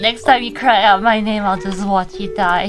Worms speechbanks
Illgetyou.wav